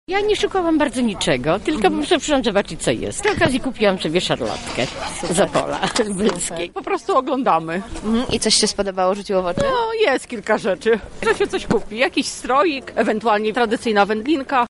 Wzdłuż Krakowskiego Przedmieścia zawitał Jarmark Świąteczny